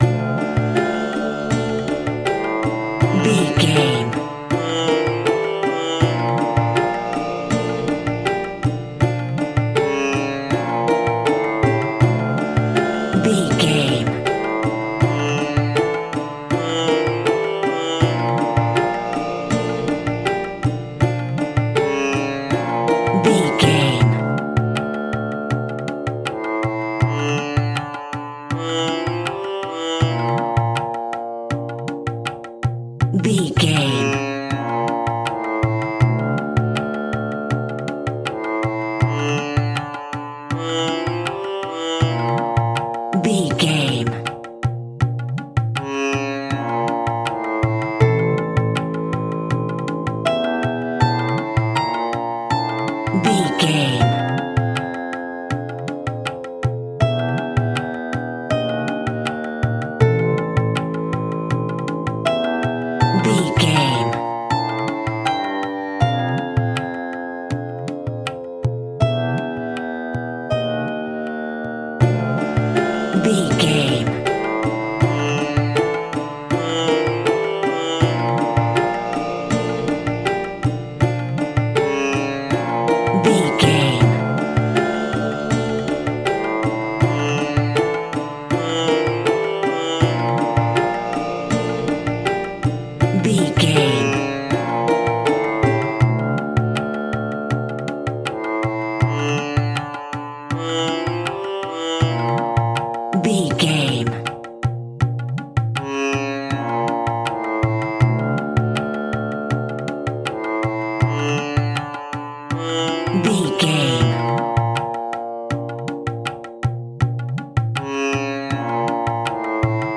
Aeolian/Minor
ominous
dark
suspense
haunting
eerie
strings
percussion
tabla
synthesiser
piano
ambience
pads